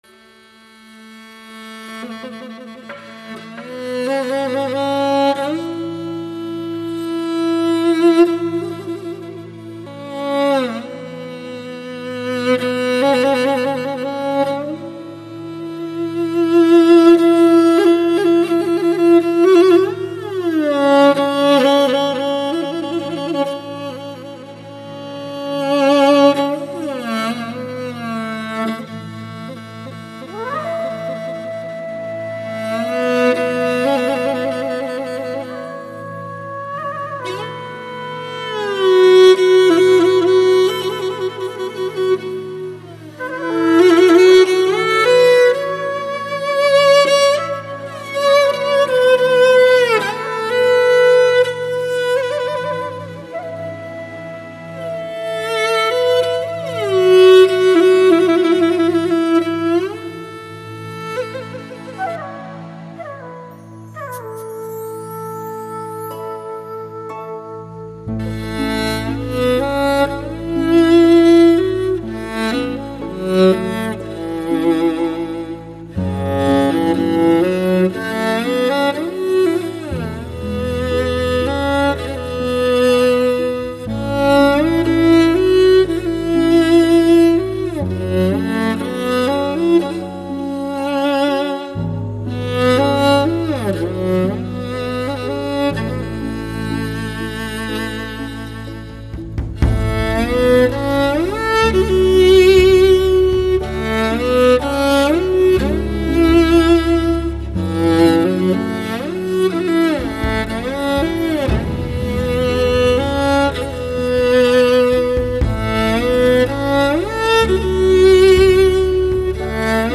一张还原草原本色的真实专辑/知抒胸臆的真情鸣唱